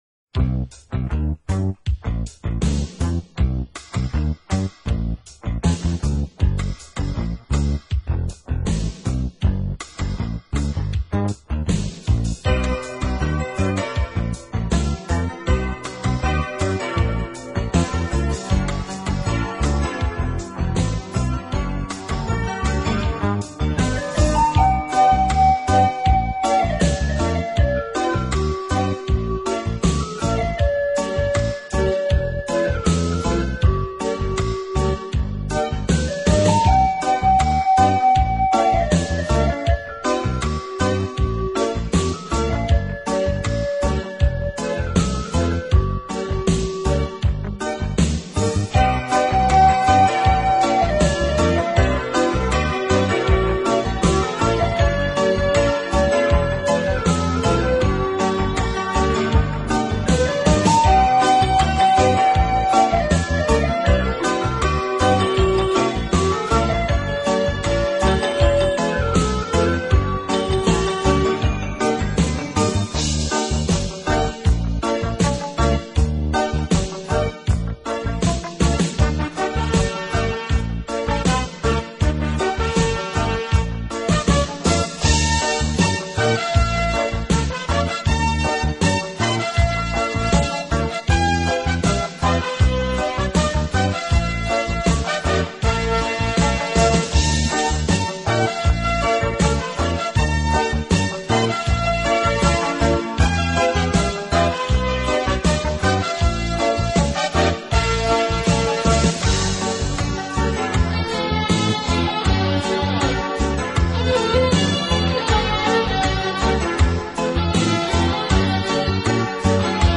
音乐类型：NewAge 新世纪